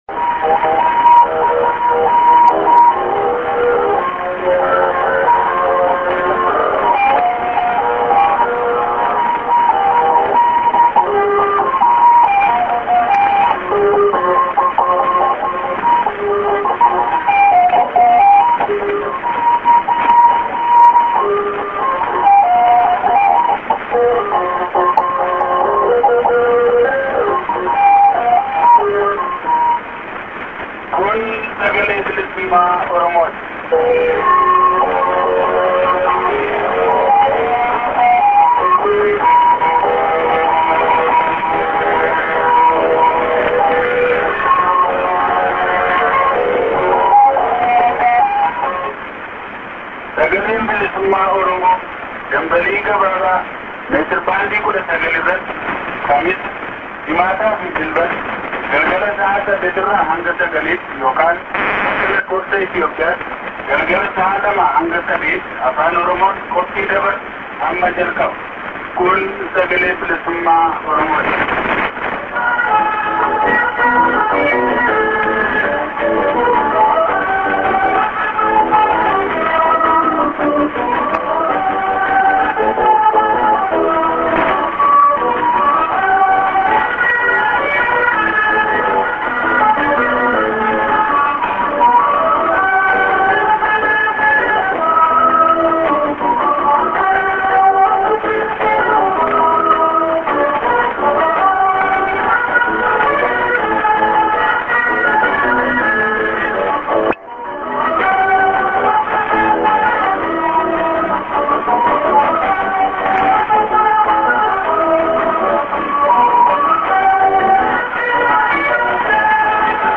St. ST->ID:--oromo--(man->)->ST->ID(man)->music->prog->